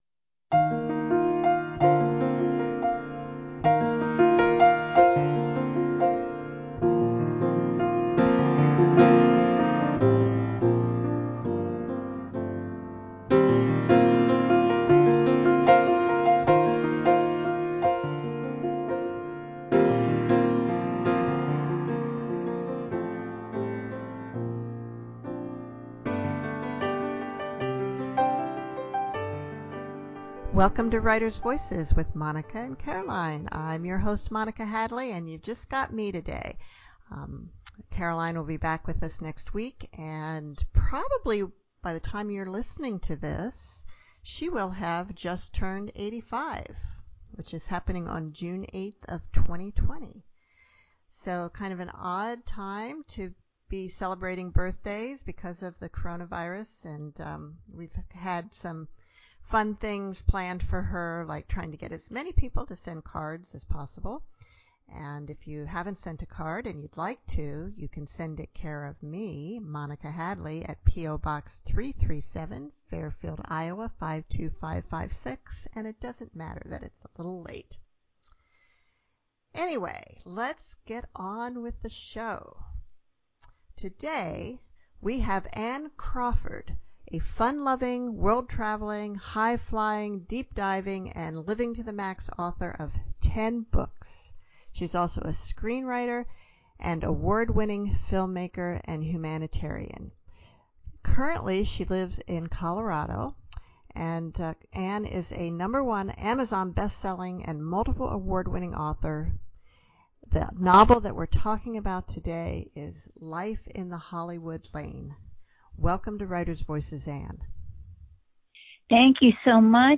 In this conversation, we talk about writing ritual, comedy improv, documentary filmmaking, and the ever-changing publishing industry and what do do when your books don’t quite fit the normal genre classifications.